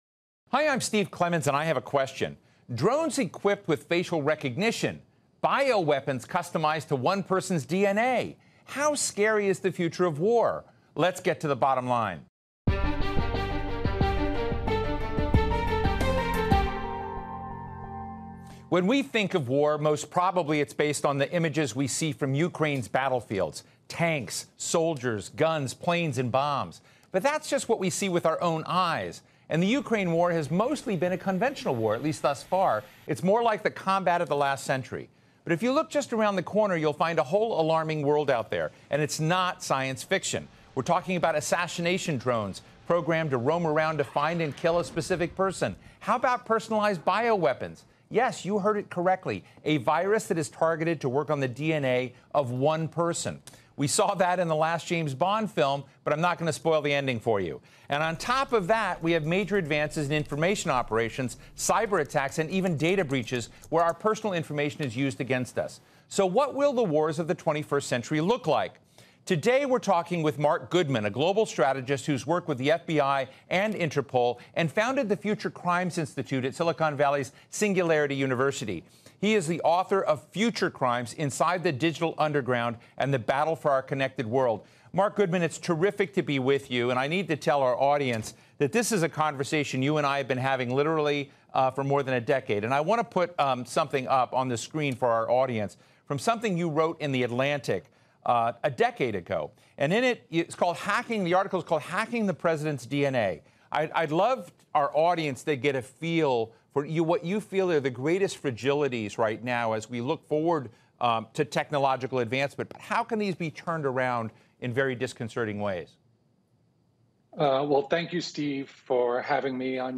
Futurist and security consultant Marc Goodman tells host Steve Clemons about the scientifi